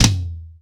TOM     3B.wav